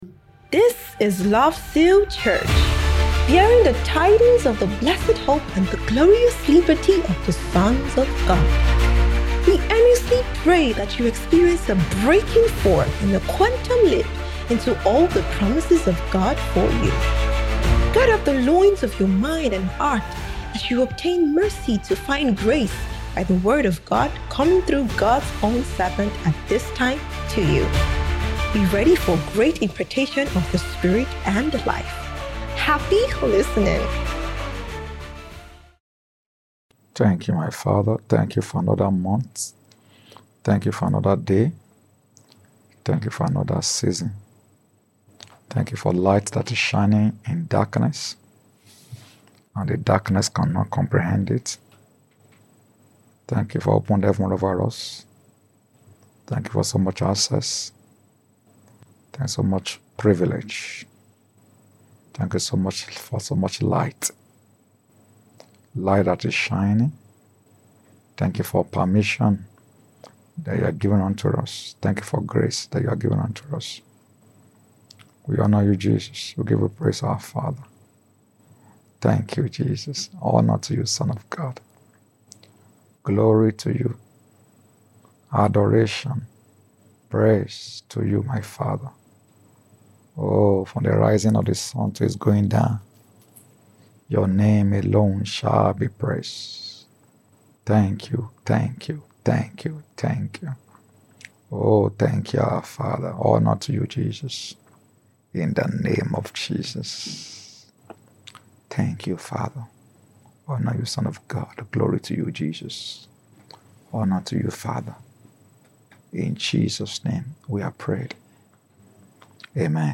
SOBT - SPECIAL MIDWEEK TEACHING SERIES